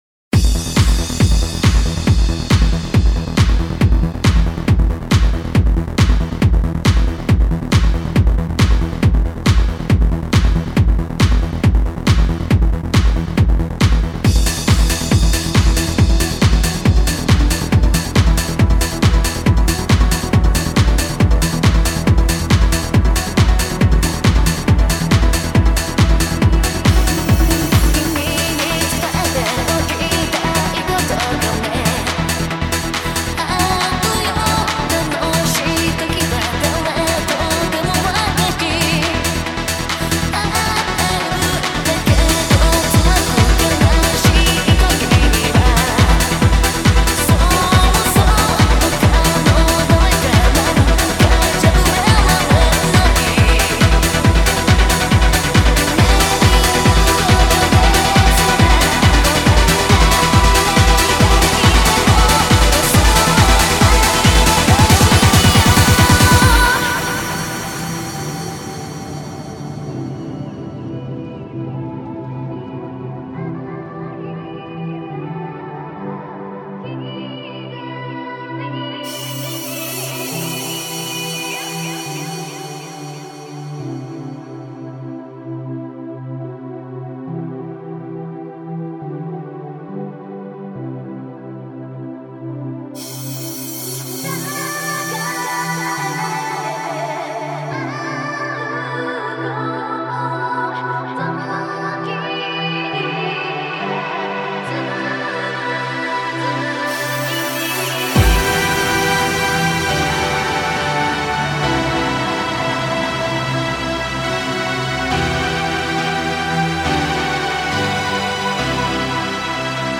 Замечательный и бодрящий трек, всем рекомендую!